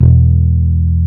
HARD FINGE00.wav